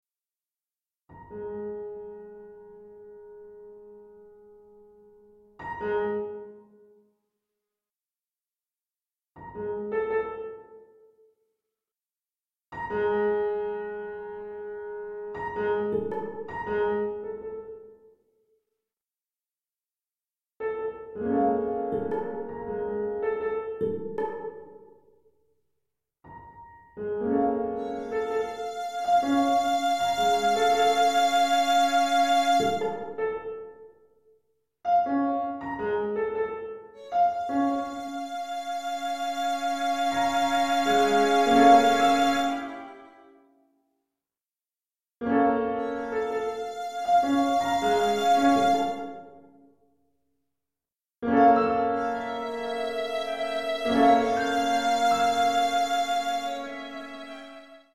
Genre: Classical.